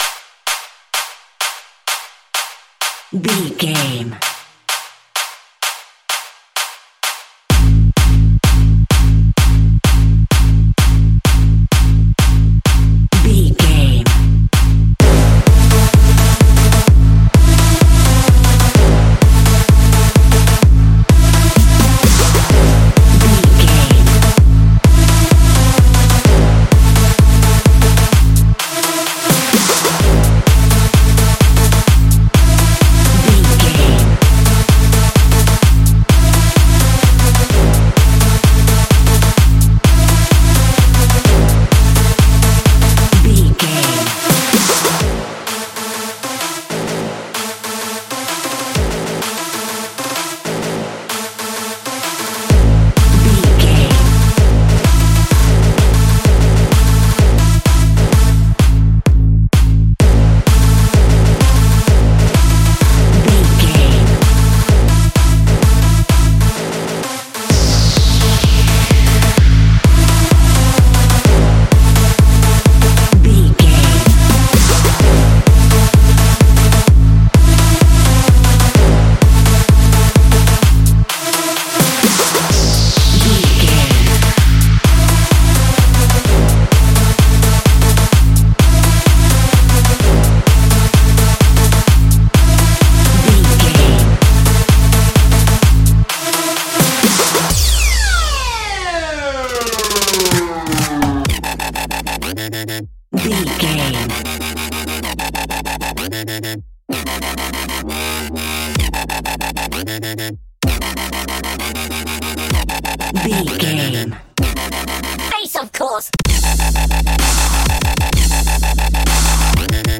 Trance Fusion Dubstep Music.
Epic / Action
Fast paced
Aeolian/Minor
aggressive
dark
energetic
drum machine
synthesiser
breakbeat
instrumentals
synth leads
synth bass